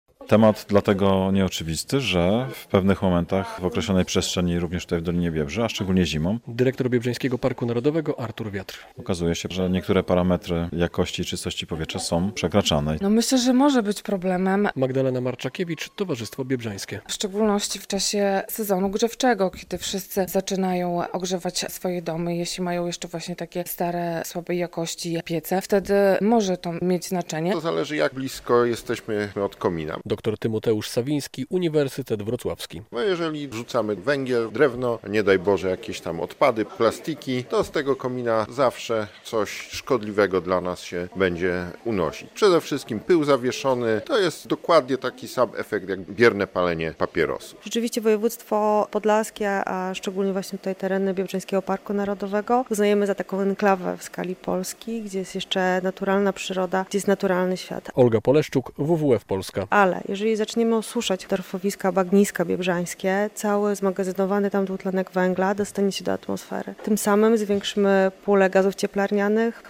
W siedzibie Parku, w Osowcu Twierdzy, miłośnicy przyrody spotkali się z naukowcami, którzy opowiadali o tym, że nawet w tak naturalnych terenach musimy dbać o to, czym oddychamy.